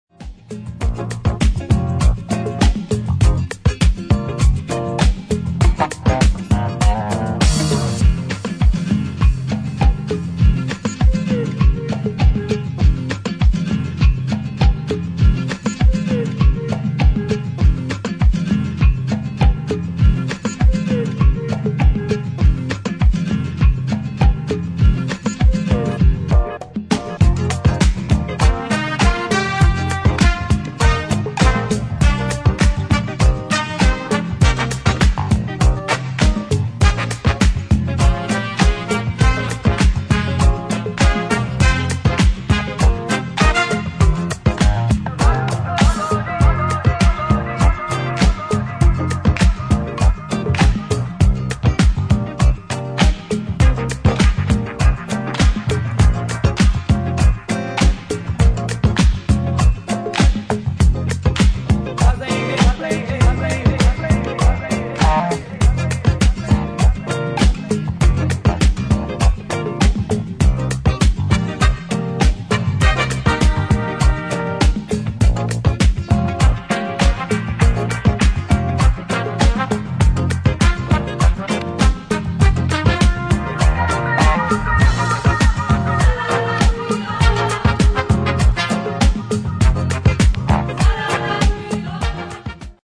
[ DISCO / JAZZ ]